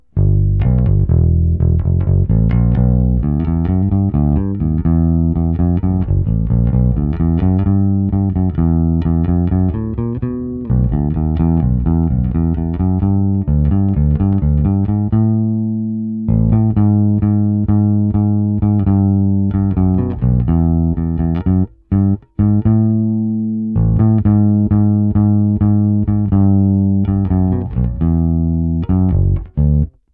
Precision, lehce stažená tónová clona, předzesilovač Fender TBP 1 s korekcemi narovno.
Nahrával jsem to i s puštěným aparátem na "normální" hlasitost, stojící kousek u aparátu, aby byl slyšet rozdíl v otočené fázi.
HPF - otočená fáze